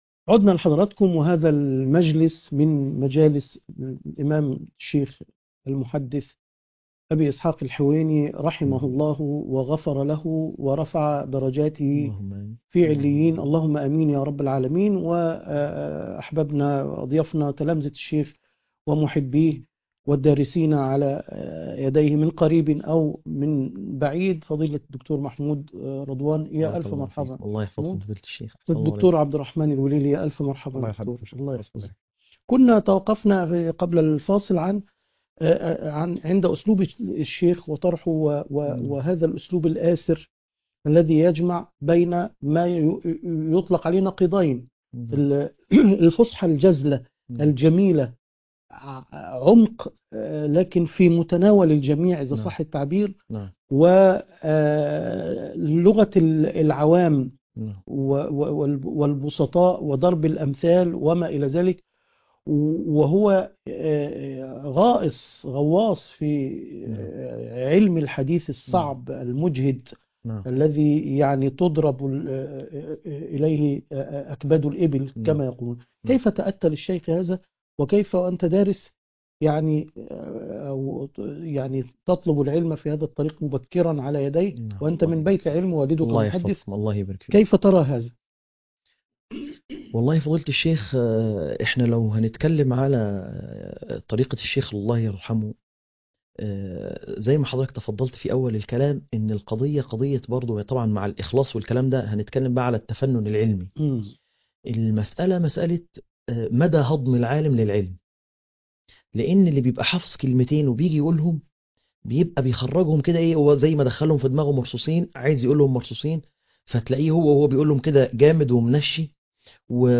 الشيخ الحويني وفقه الواقع _ تأبين الشيخ الحويني _ مع نخبة من العلماء والدعاة - قسم المنوعات